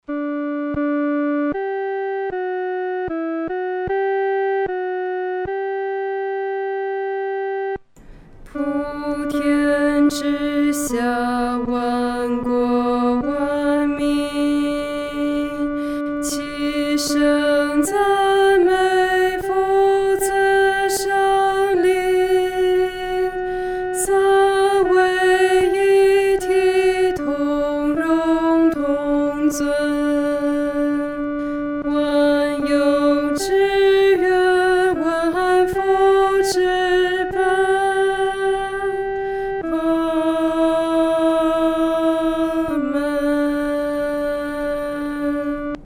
独唱（第二声）